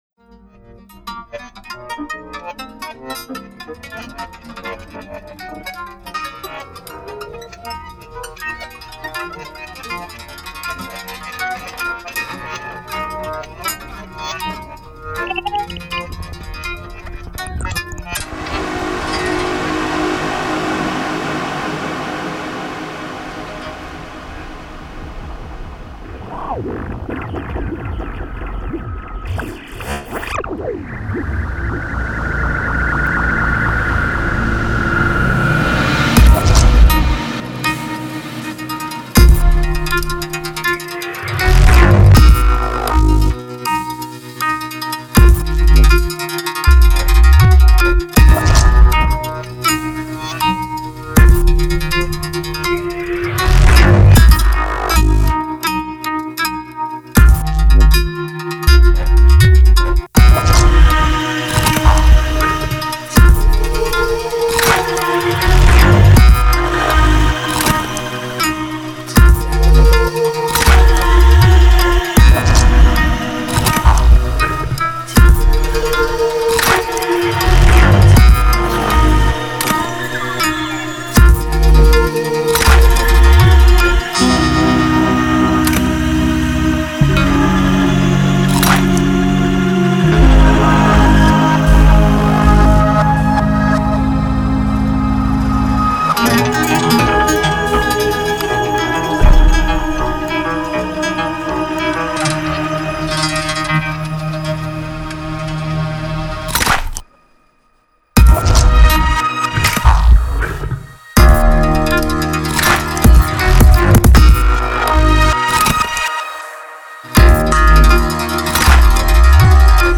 Жанр: Electronic.